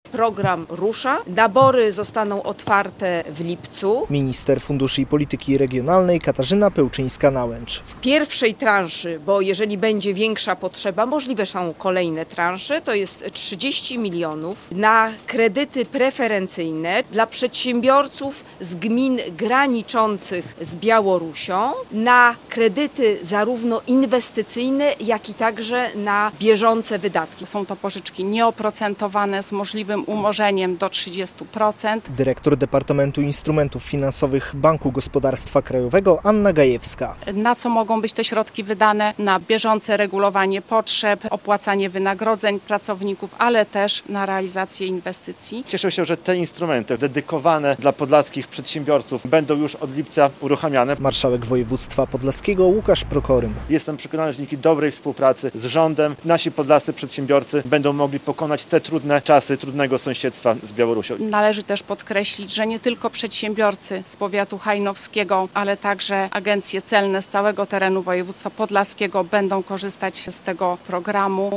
30 mln zł będzie przeznaczone na rozpoczynający się w lipcu program preferencyjnych pożyczek dla firm ze strefy przy granicy z Białorusią - poinformowała w środę (25.06) podczas konferencji prasowej w Nieznanym Borze minister funduszy i polityki regionalnej Katarzyna Pełczyńska-Nałęcz.
Nowy program pożyczek dla firm przygranicznych - relacja